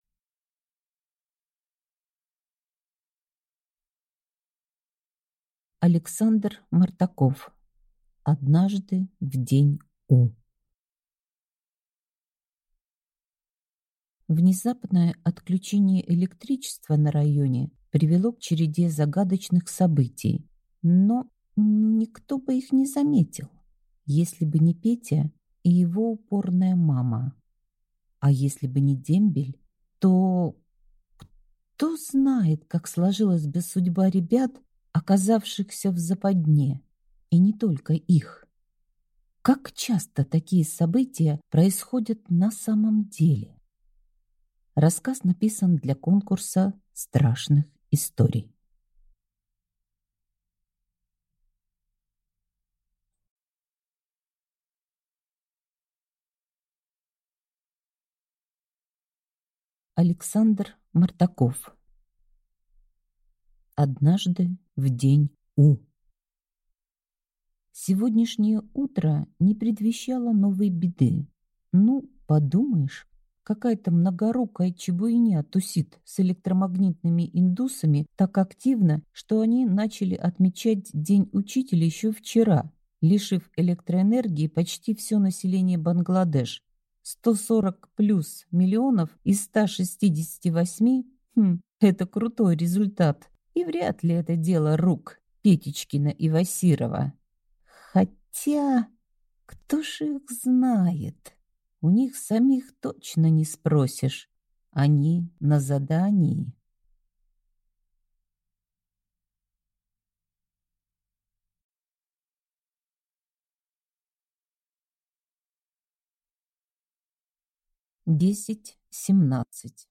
Аудиокнига Однажды в День У | Библиотека аудиокниг